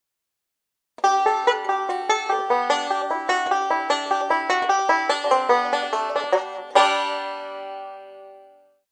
BANJO STANDARD
JDE O NÁSTROJ KLASICKÉ MASTERTONE KONSTRUKCE VYHOVUJÍCÍ NÁROČNÝM ZVUKOVÝM POŽADAVKŮM. NA TENTO NÁSTROJ POUŽÍVÁM TONERING SE ZVONOVÉ BRONZI, DŘEVĚNÉ SOUČÁSTI JSOU POSTAVENY Z MASIVNÍHO DŘEVA.